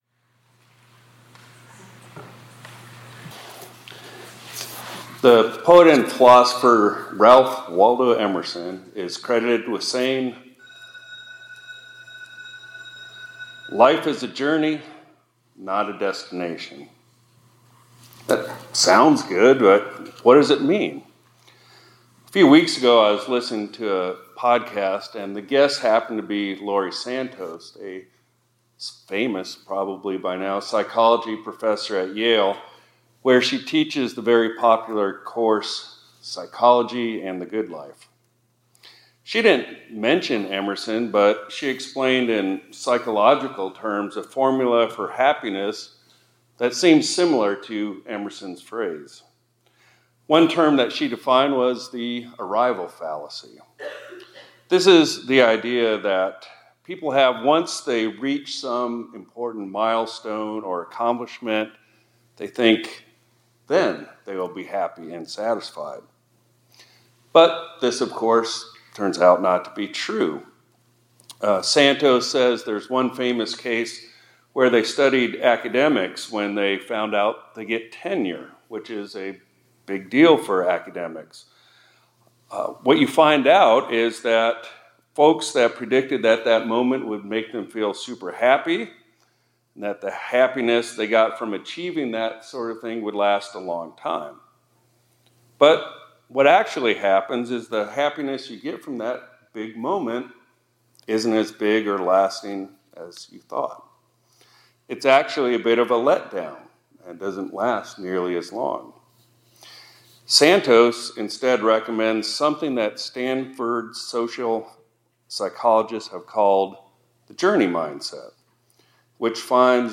2025-01-22 ILC Chapel — Your Christian Life is a Journey